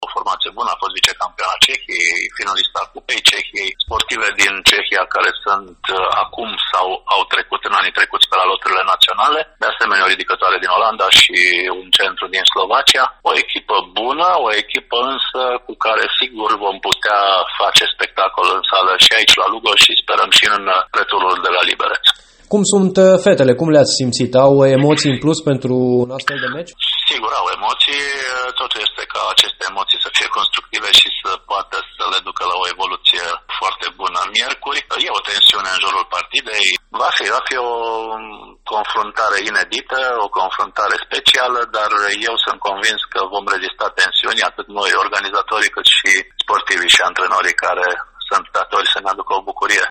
Într-un dialog